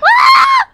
Worms speechbanks
ow1.wav